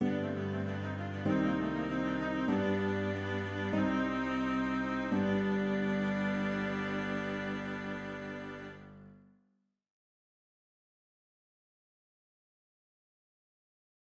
Pour des raisons de clarté auditive, les exemples audios seront ici donnés avec des sons de violoncelle, ceux ci étant préférables aux sons de voix synthétiques.
Voici maintenant la fin de cette pièce, avec un emprunt à la tonalité du quatrième degré do par la note si bécarre qui mène à une cadence plagale de de do mineur vers sol majeur, cet accord de sol majeur étant obligatoire du fait de cet emprunt.